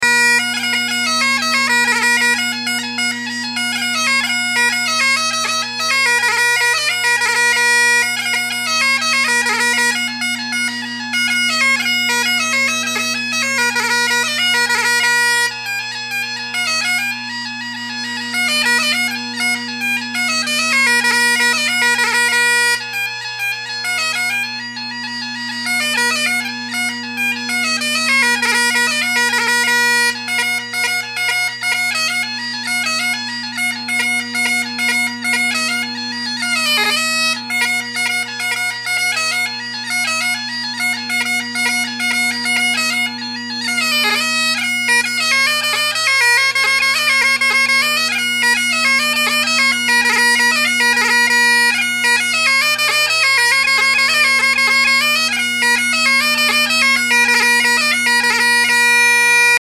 The top hand F, high G, and high A all sound very good. D is spot on, as is B. The other notes are a given.